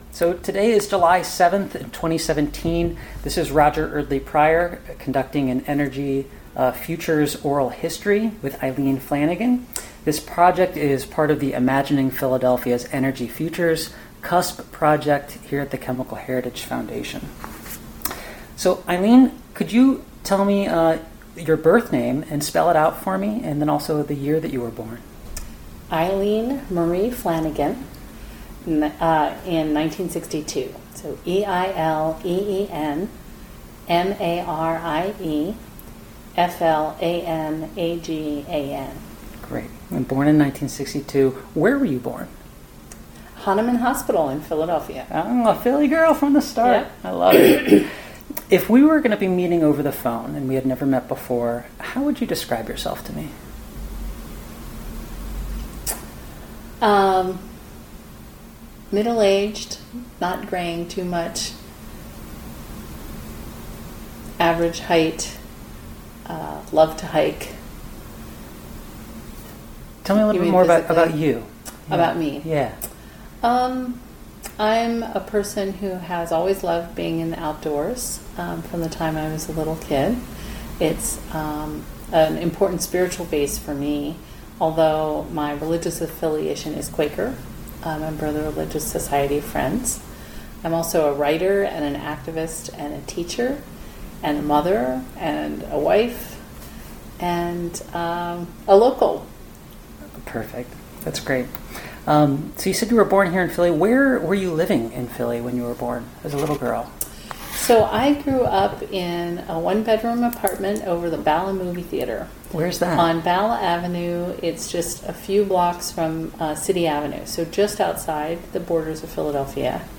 Oral histories
Place of interview Chemical Heritage Foundation Pennsylvania--Philadelphia